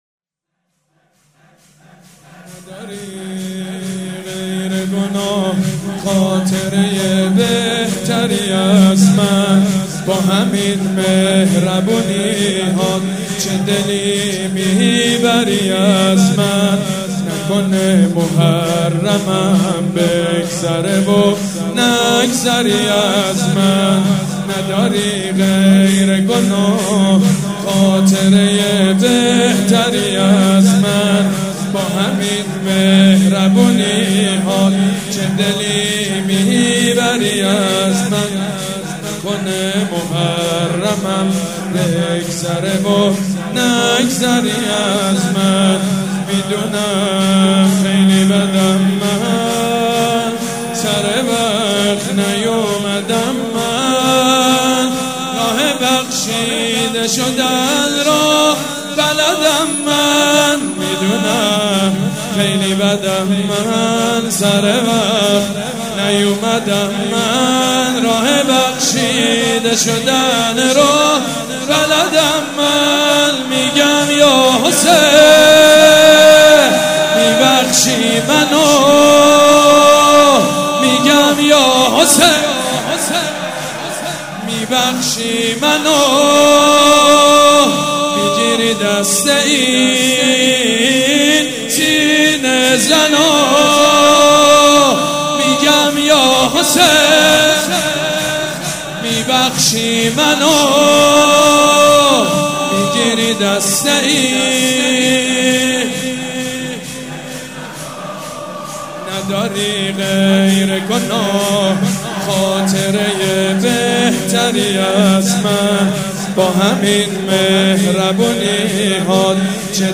شب سوم محرم الحرام‌ سه شنبه ۱3 مهرماه ۱۳۹۵ هيئت ريحانة الحسين(س)
سبک اثــر شور مداح حاج سید مجید بنی فاطمه
مراسم عزاداری شب سوم